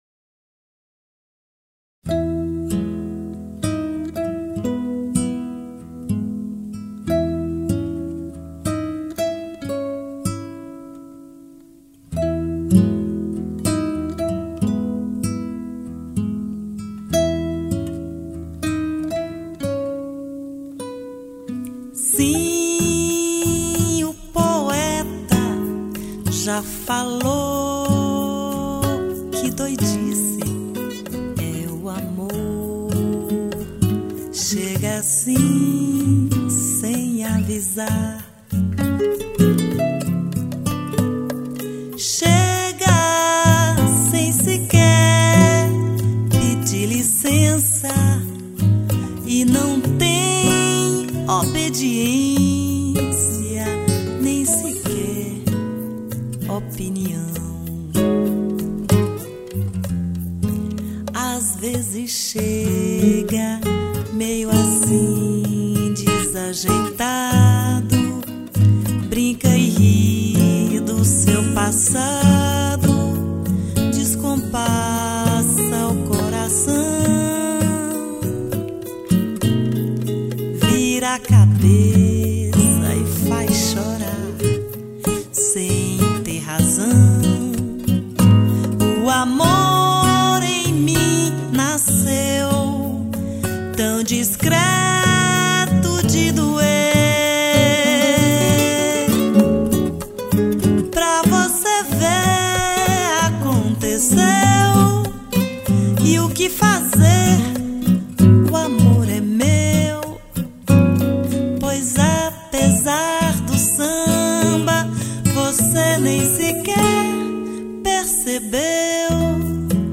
1579   04:30:00   Faixa:     Bossa nova
Violao Acústico 6
Percussão, Violao 7